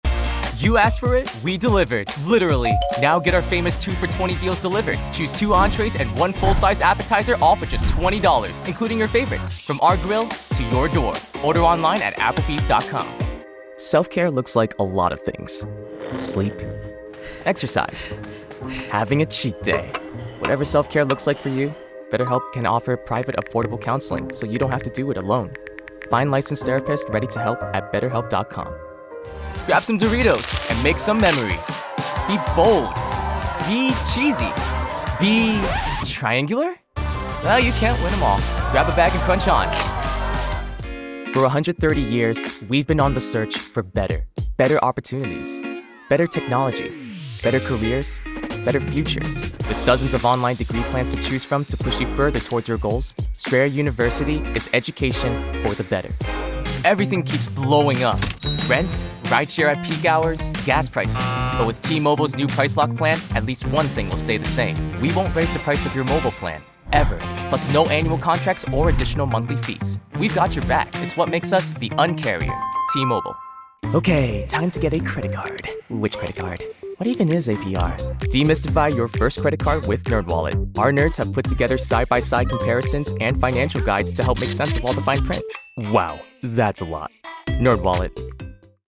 Teenager, Young Adult, Adult
COMMERCIAL 💸
conversational
sincere
warm/friendly